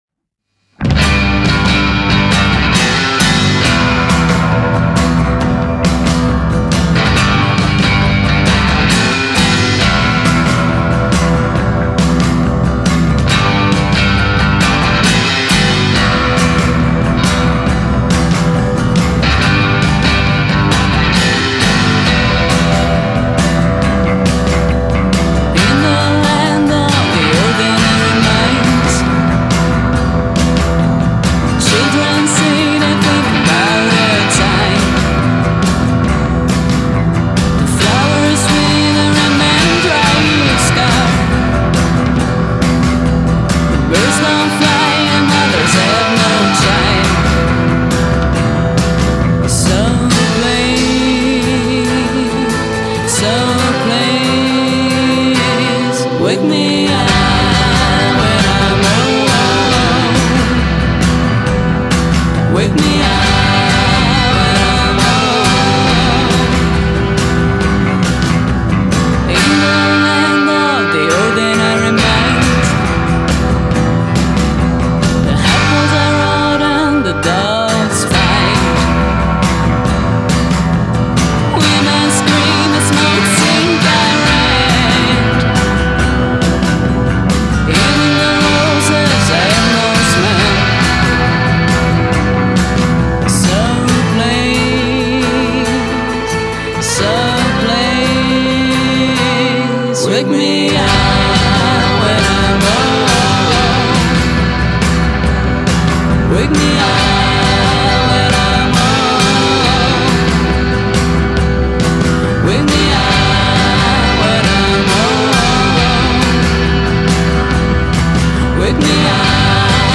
basso, voce, batteria
Una band che suona musica melodica sgangherata col cuore.